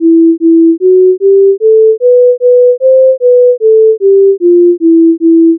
Bonjour, je recherche une mélodie avec ces notes:
Mi - Mi - Fa Diese - Sol - La - Si - Si - Do - Si - La - Sol - Fa - Mi - Mi
melody_playback-wav.wav